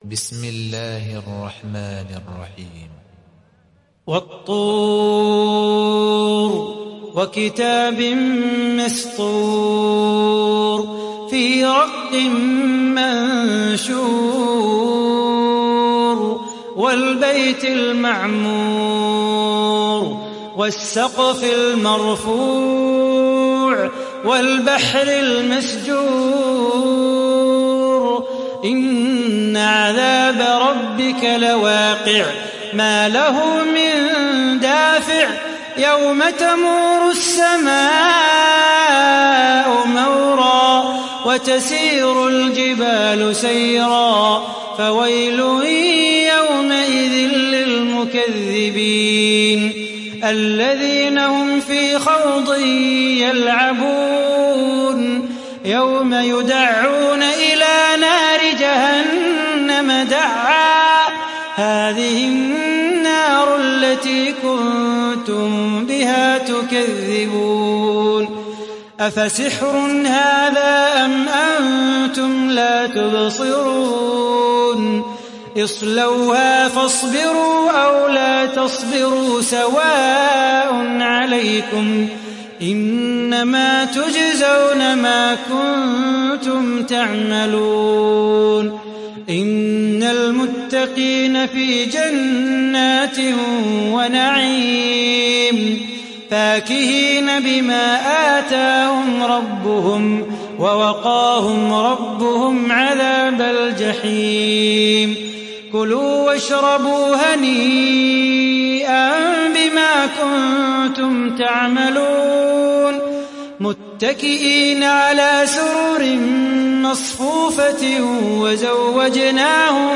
دانلود سوره الطور mp3 صلاح بو خاطر (روایت حفص)